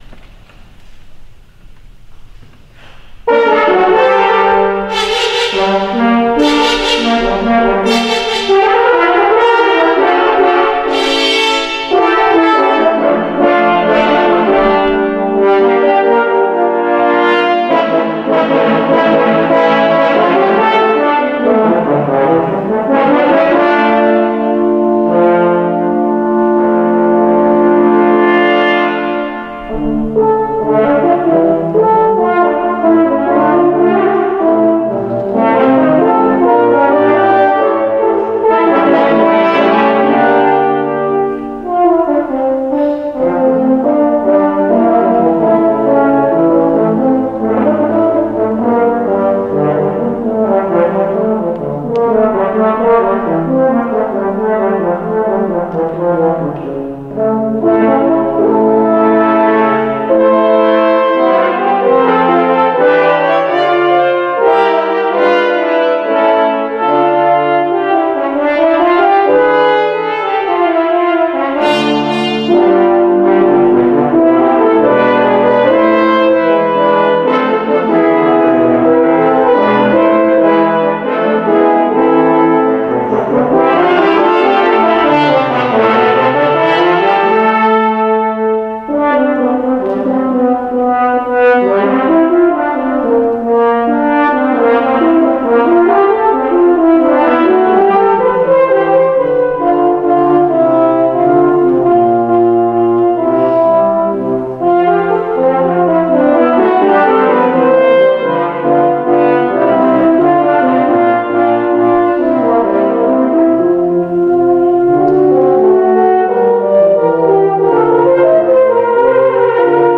Voicing: French Horn Quartet